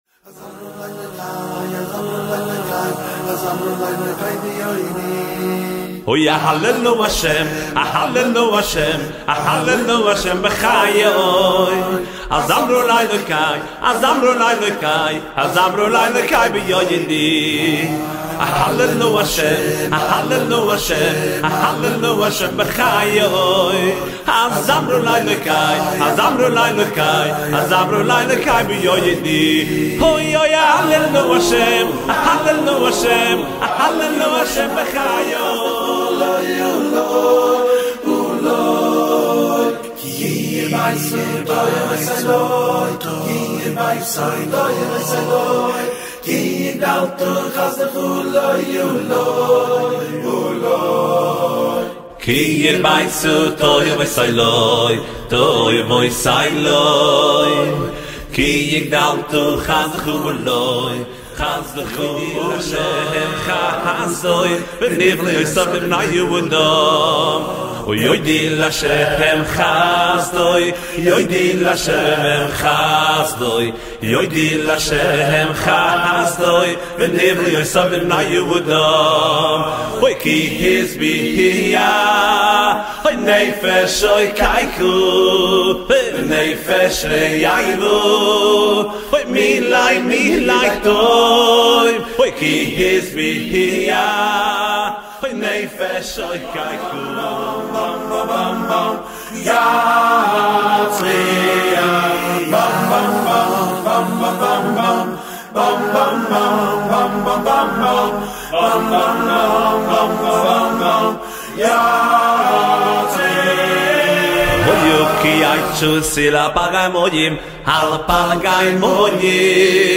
ווקאלי